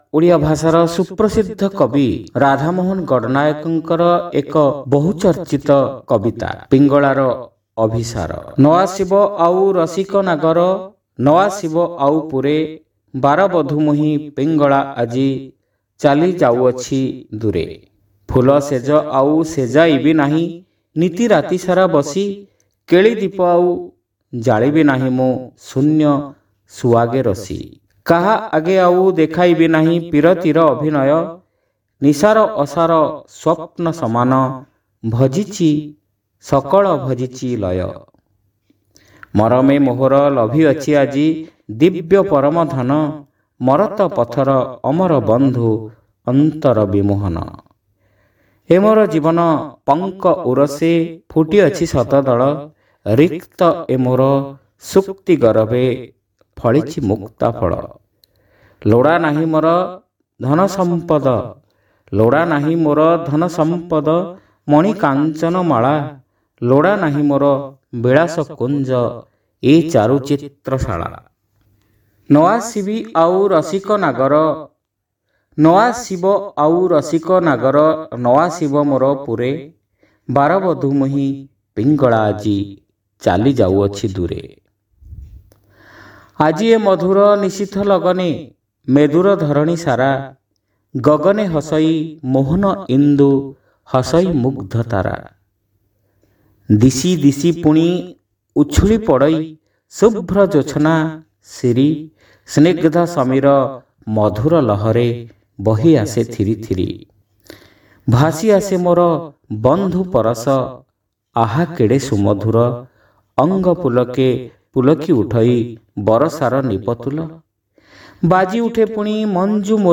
Audio Poems : Pingalara Abhisara